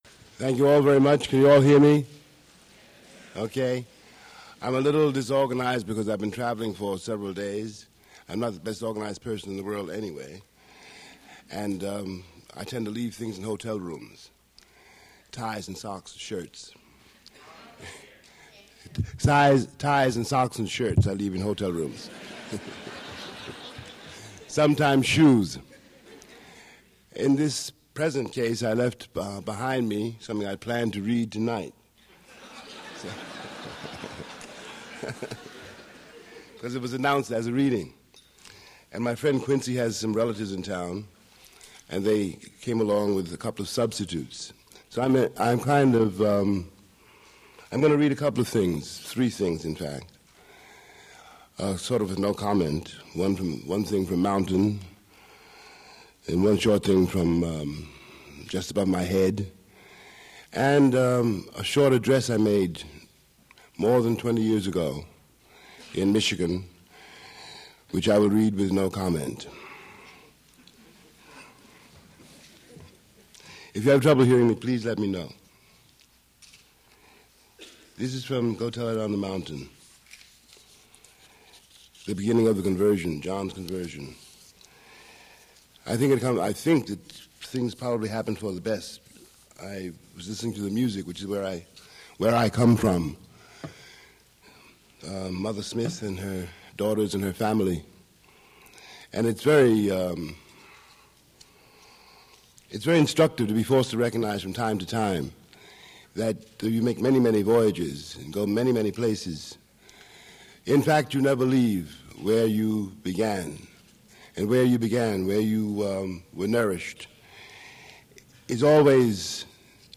Attributes Attribute Name Values Description James Baldwin poetry reading at Duff's Restaurant. Recording Index: 00:00 James's own intro; 02:30 John fiction; 07:48 the end of Just Above My Head fiction; 17:46 In Search of a Majority speech
Source mp3 edited access file was created from unedited access file which was sourced from preservation WAV file that was generated from original audio cassette.